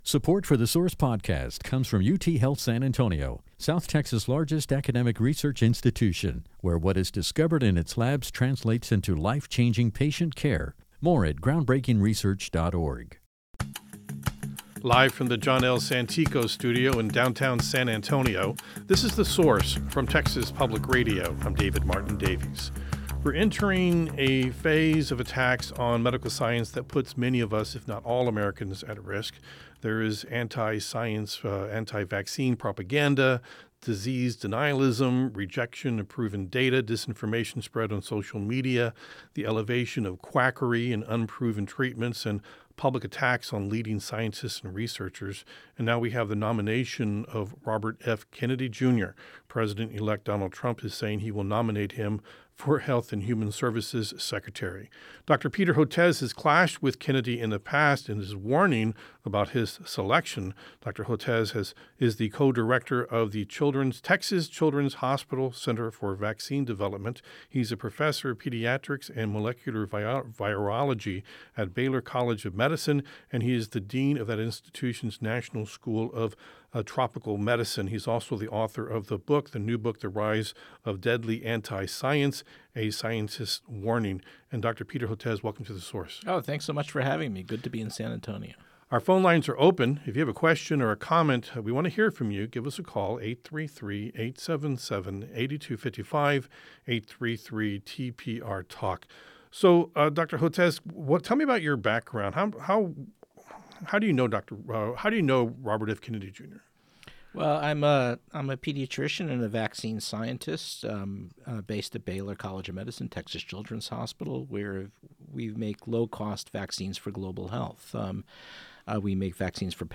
Anti-Vaccine and medical misinformation spreader Robert F. Kennedy Jr. has been tapped by President-elect Donald Trump to be the health secretary. Vaccine researcher, Dr. Peter Hotez, joins us to explain why this is a concerning choice.